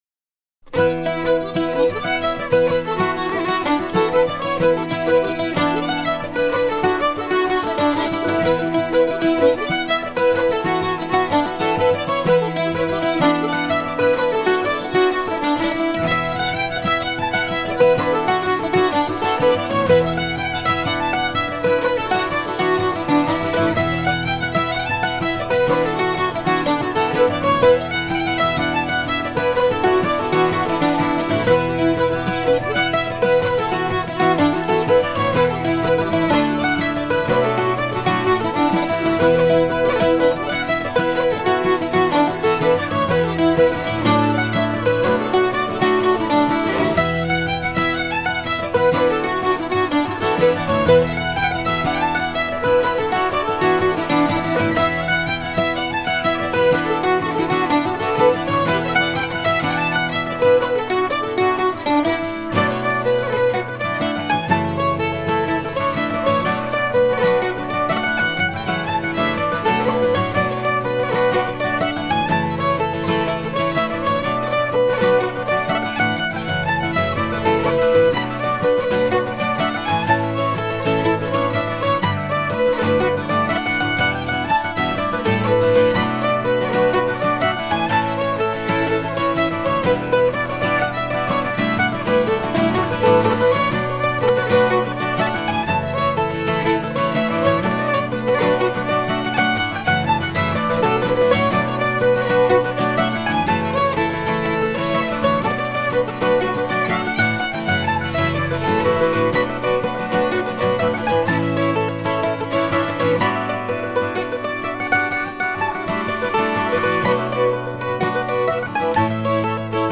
(demo 2003)
1- Jigs
1-2-3-4 : enregistré / recorded "at home" (Marcq-en-Baroeul)